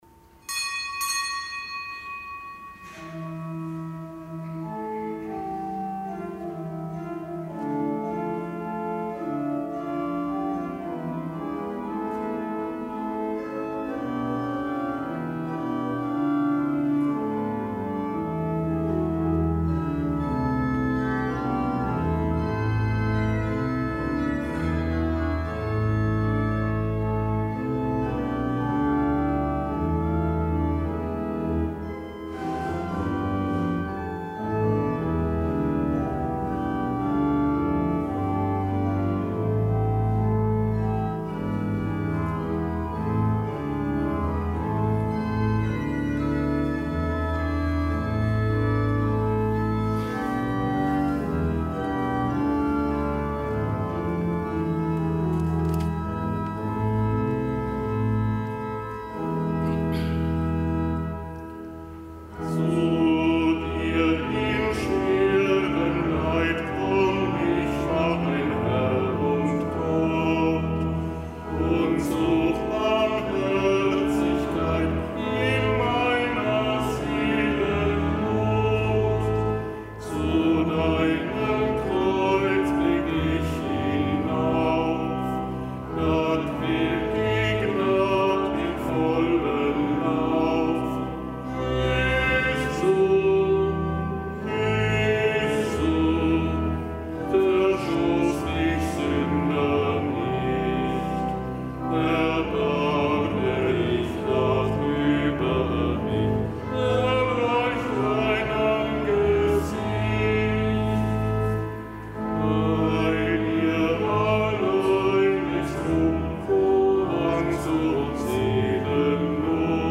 Kapitelsmesse am Samstag der fünften Fastenwoche
Kapitelsmesse aus dem Kölner Dom am Samstag der fünften Fastenwoche